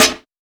Dilla Snare 10.wav